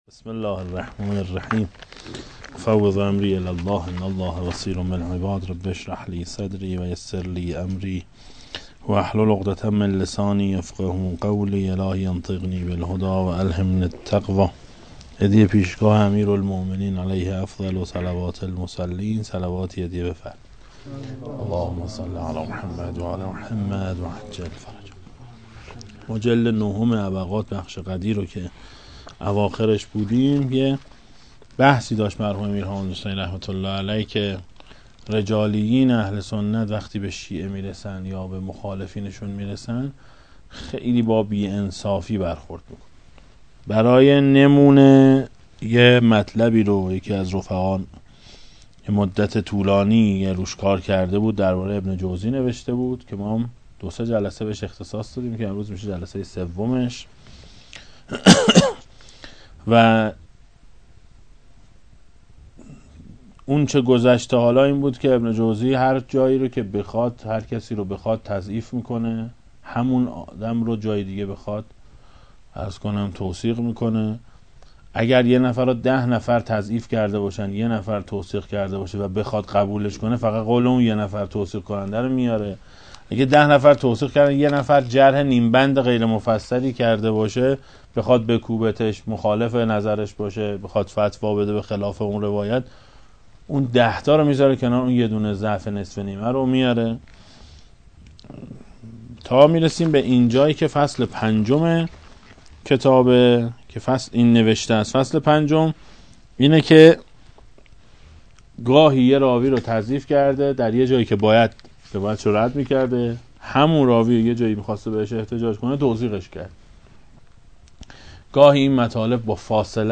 در مدرس پژوهش حوزه علمیه امام خمینی (ره) تهران برگزار گردید